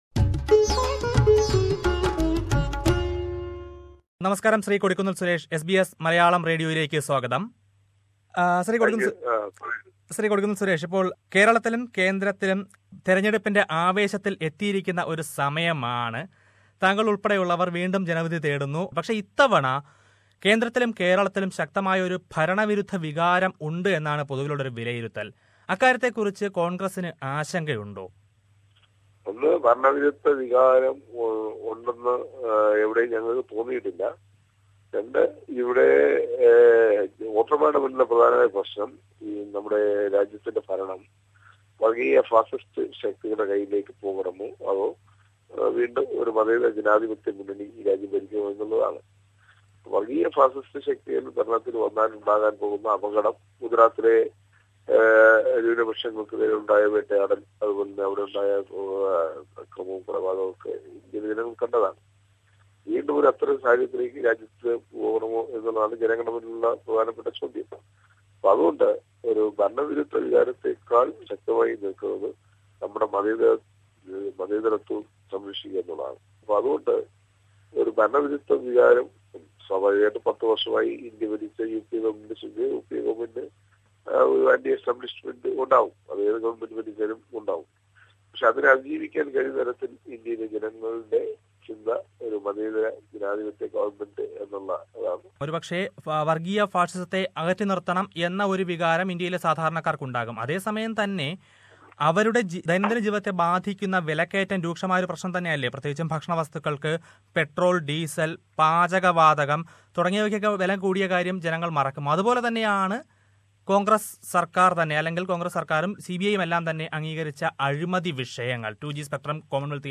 SBS Malayalam Radio interviews leaders from all political parties ahead of the Indian elections. This week, let us listen to Congress MP and candidate Kodikkunnil Suresh .